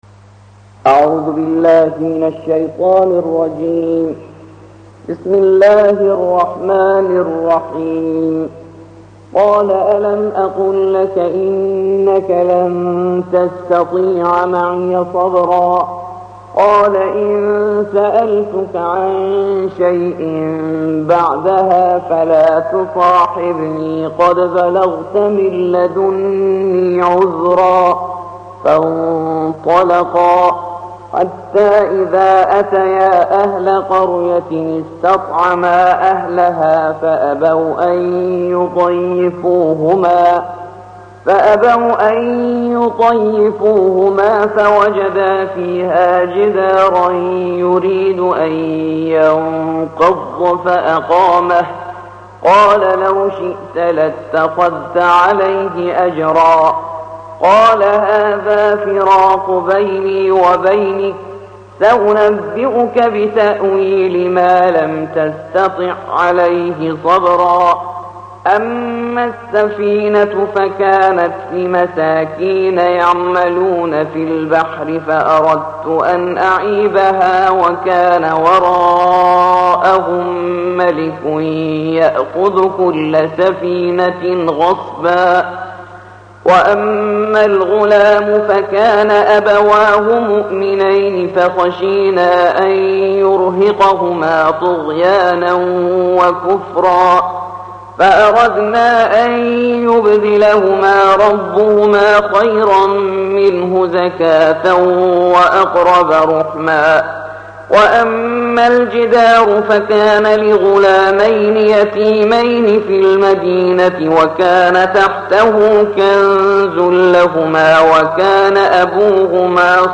الجزء السادس عشر / القارئ